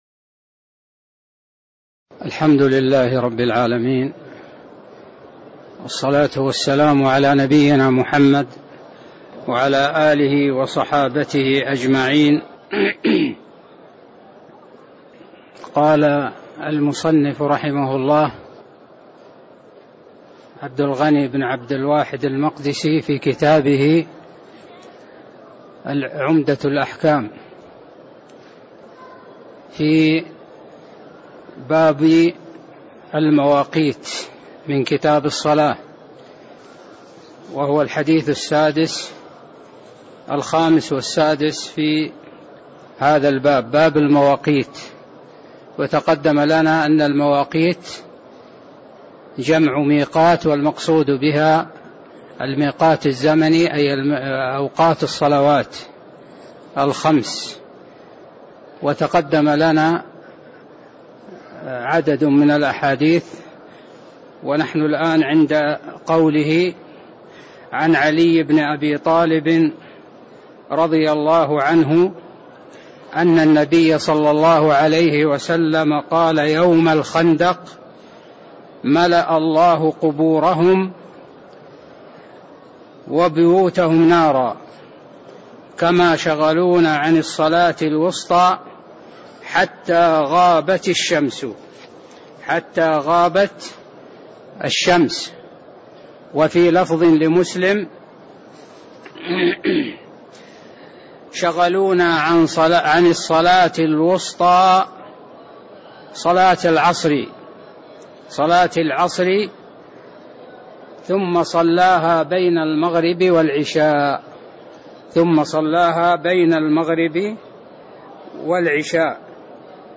تاريخ النشر ١٢ شعبان ١٤٣٥ هـ المكان: المسجد النبوي الشيخ